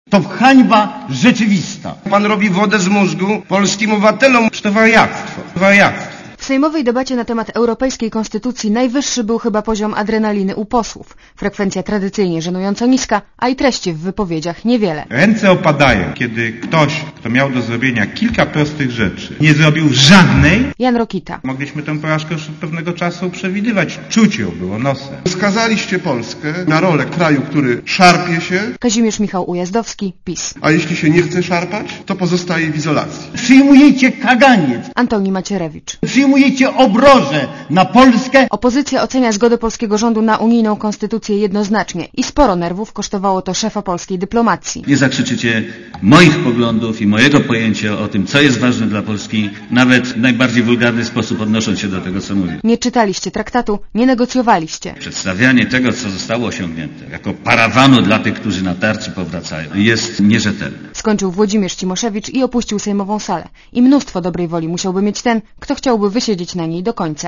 Tak wyglądała sejmowa debata nad europejską konstytucją.